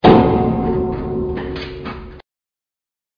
SFX尴尬时的咚声音效下载
SFX音效